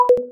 check-off.wav